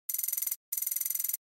typing_sound.mp3